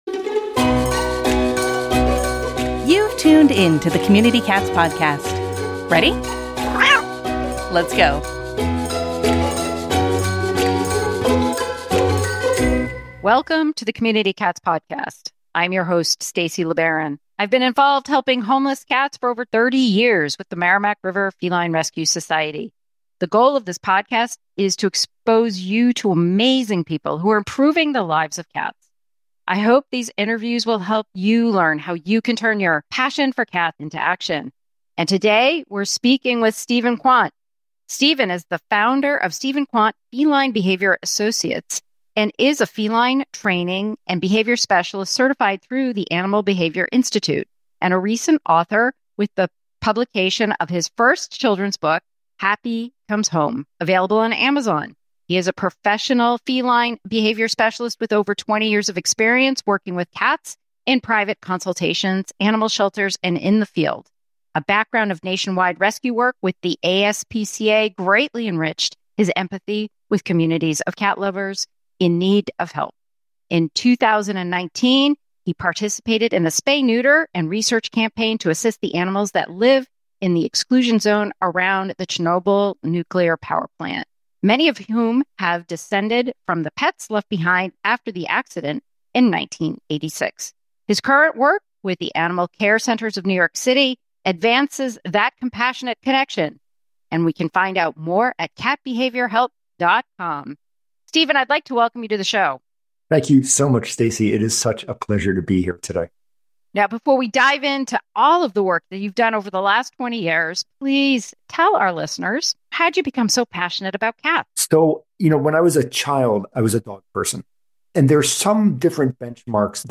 In this enlightening and heartwarming conversation